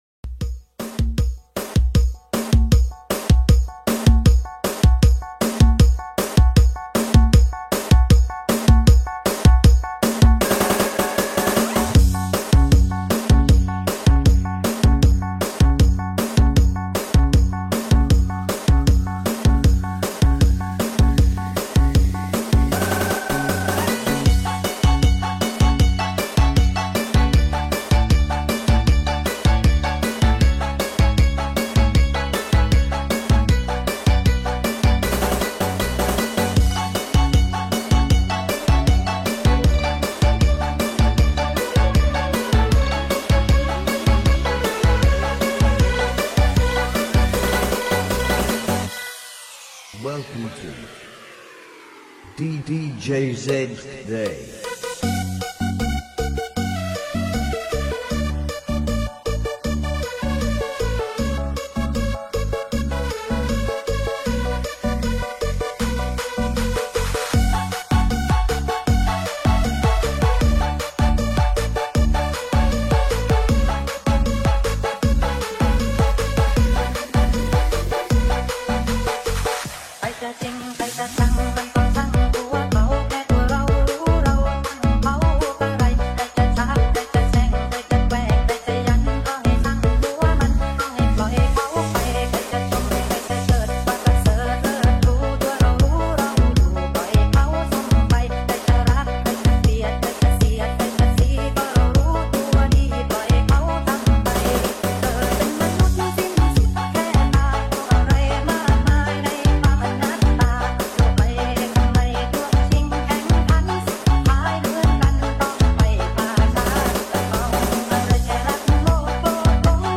Thailand Remix